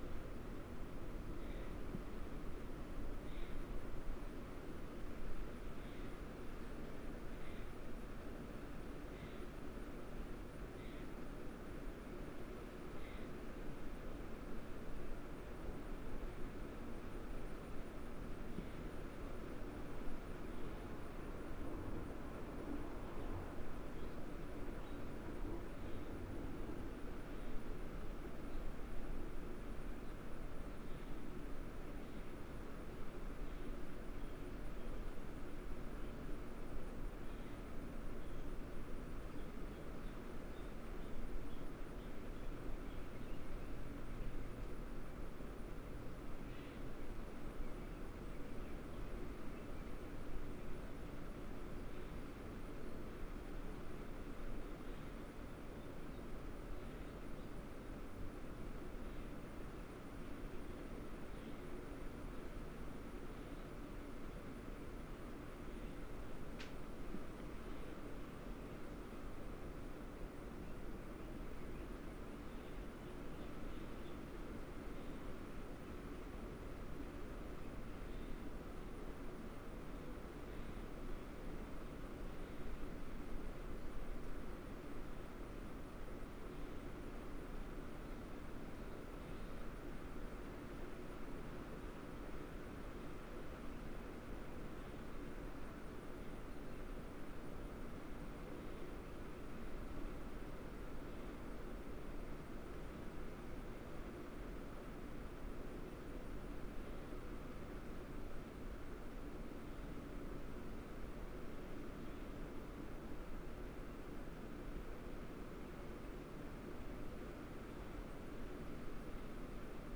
Ambient_InteriorLoop.wav